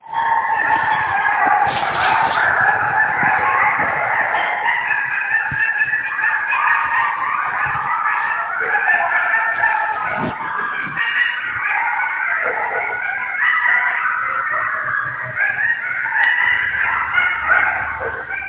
Clatskanie Coyote
We very rarely see the coyotes, although we sometimes hear them late at at night on the hill behind our place.